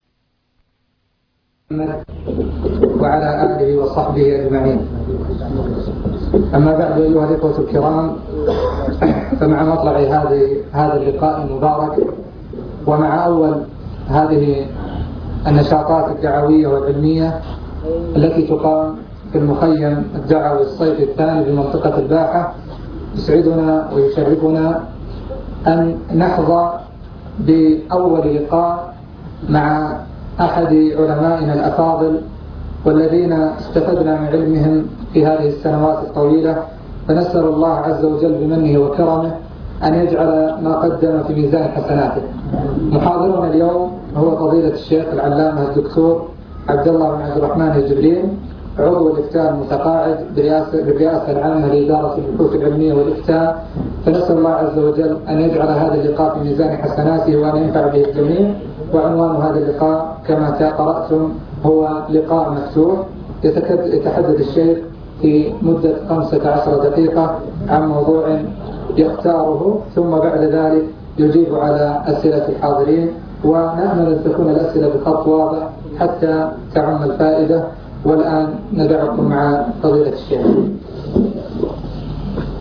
المكتبة الصوتية  تسجيلات - محاضرات ودروس  محاضرات في محافظة الباحة
تقديم